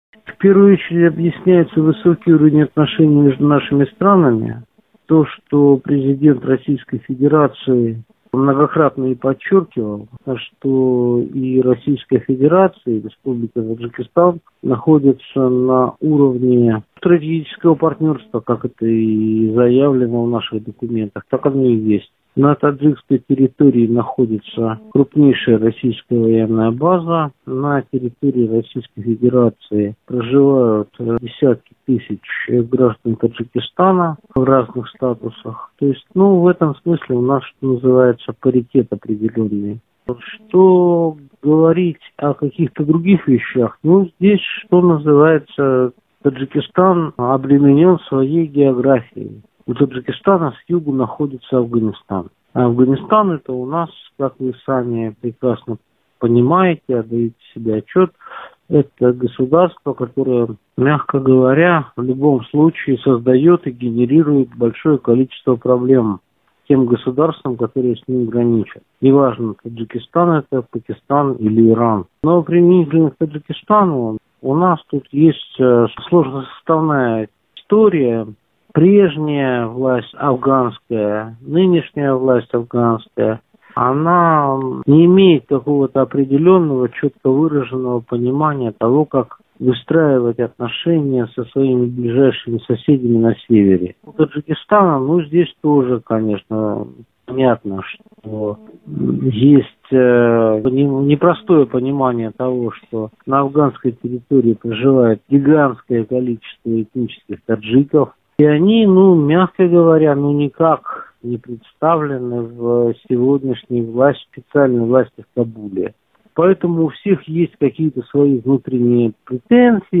аудиоверсия программы